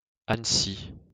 Annecy (US: /ˌænəˈs, ɑːnˈs/ AN-ə-SEE, ahn-SEE,[3][4] French: [an(ə)si]